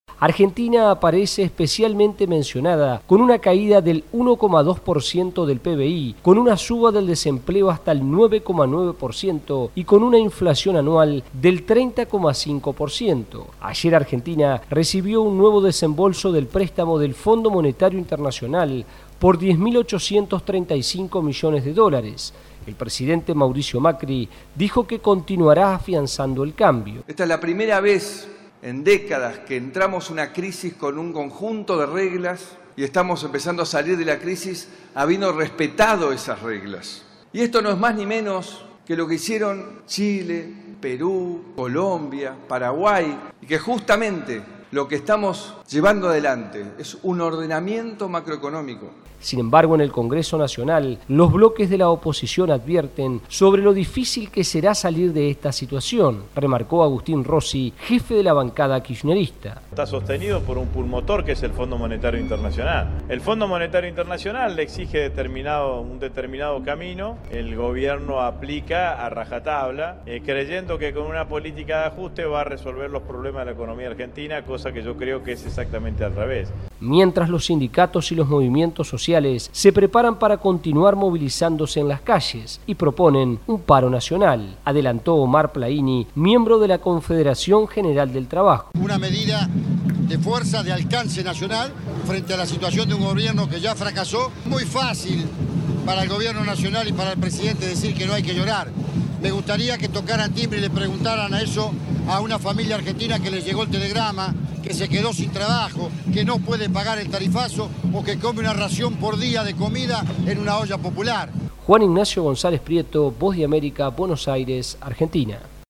VOA: Informe desde Argentina